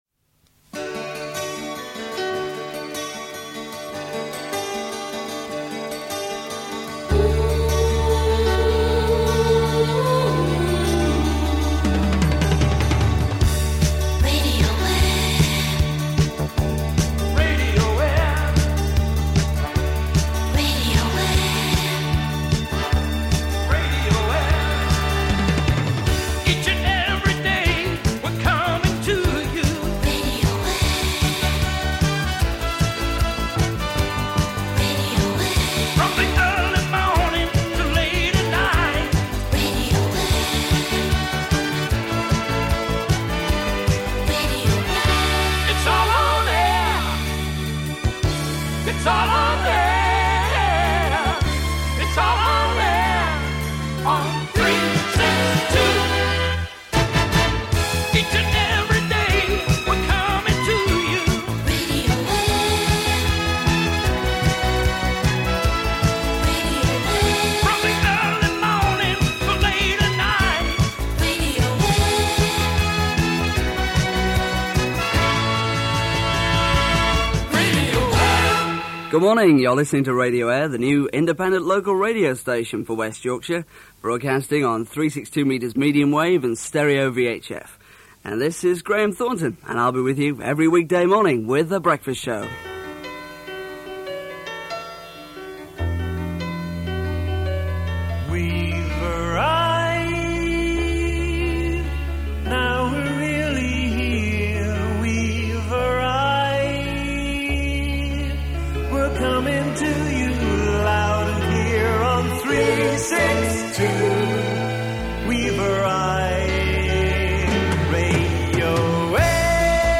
Radio Aire launch - 1981